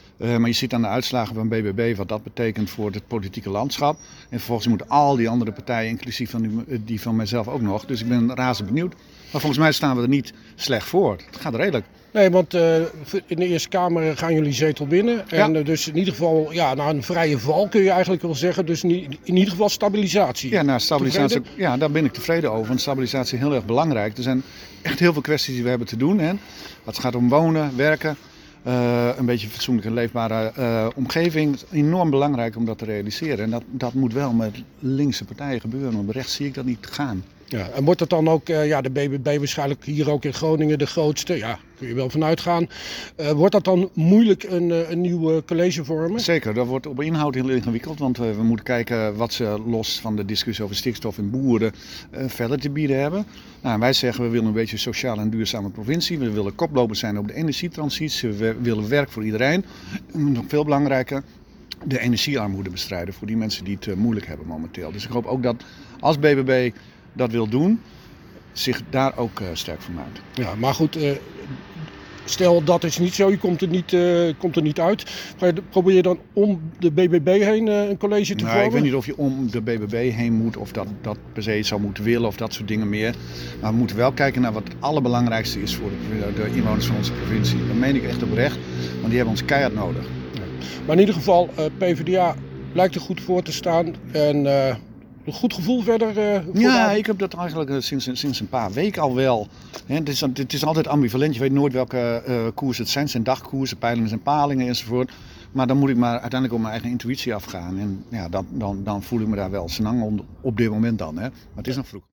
sprak met lijsttrekker Tjeerd van Dekken van de PvdA:
Lijsttrekker Tjeerd van Dekken van de PvdA wordt geïnterviewd door OOG Tv.
InvTjeerdvanDekken.mp3